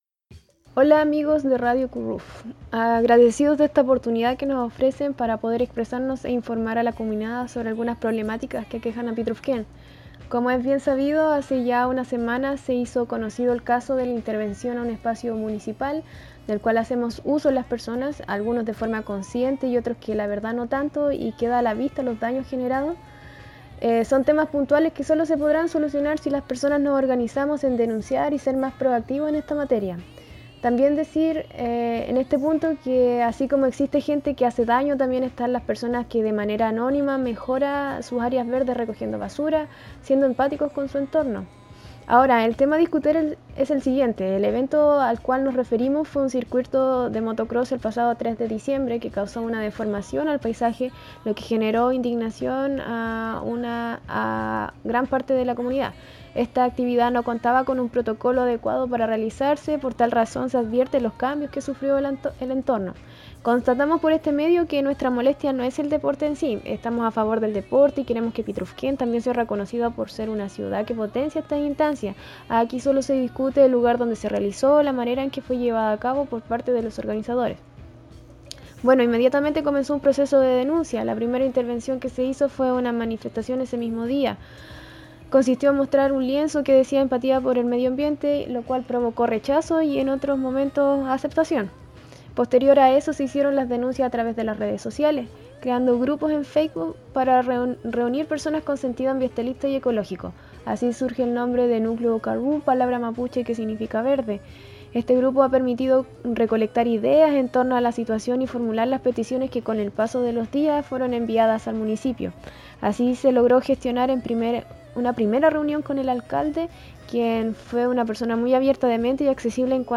Sobre ésta y otras situaciones nos comenta una de las integrantes de Núcleo Karu en el siguiente audio.